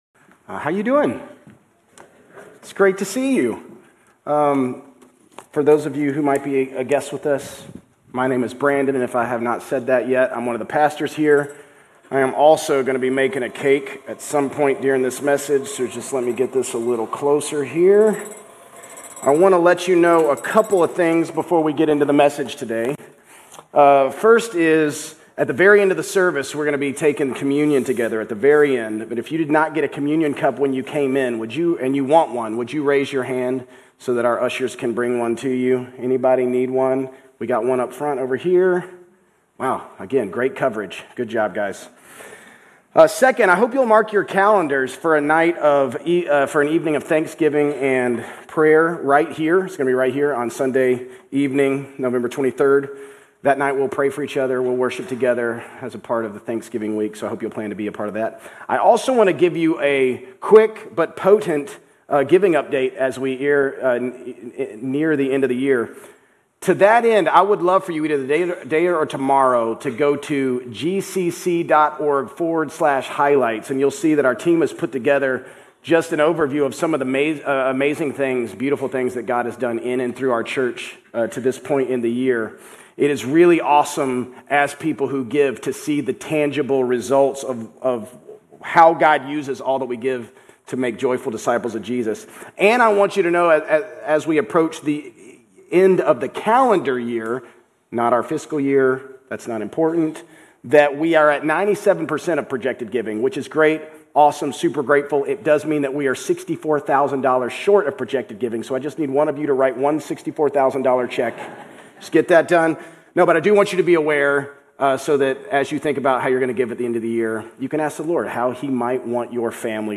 Grace Community Church Old Jacksonville Campus Sermons 11_2 Old Jacksonville Campus Nov 03 2025 | 00:35:43 1x 00:00 / 00:35:43 Subscribe Share RSS Feed Share Link Embed